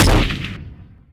bullet_hunter.wav